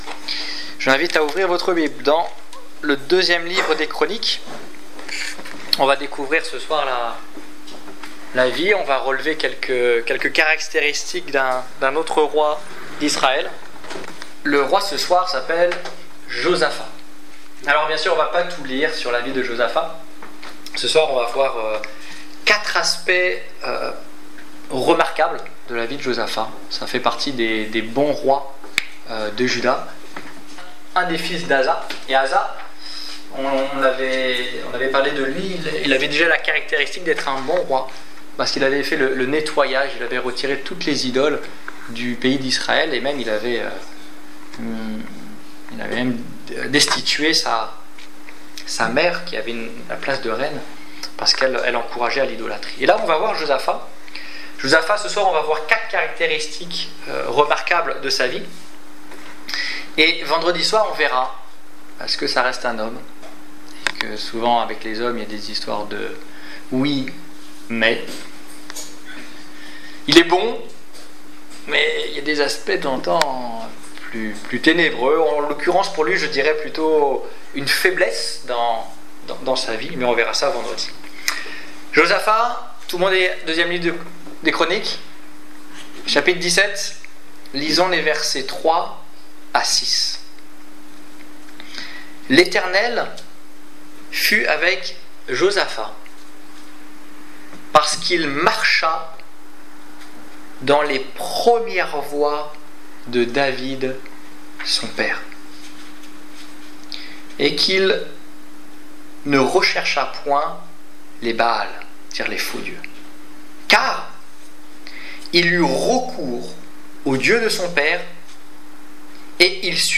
Étude biblique du 20 juillet 2016